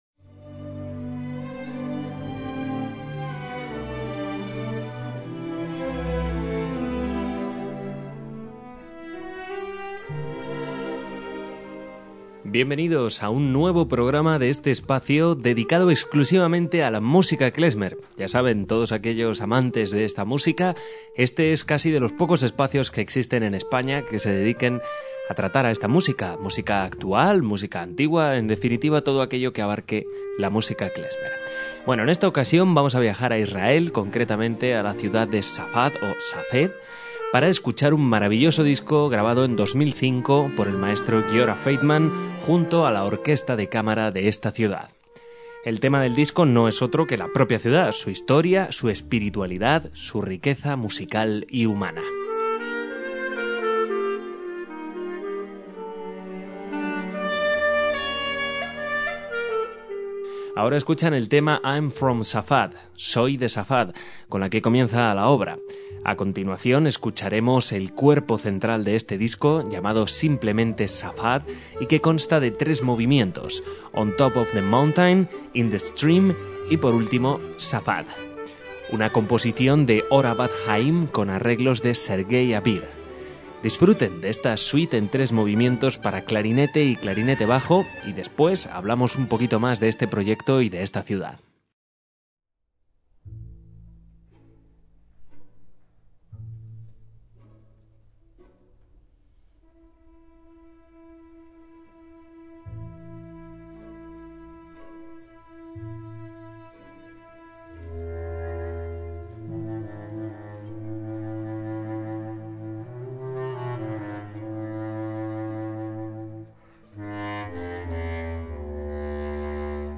MÚSICA KLEZMER
clarinetista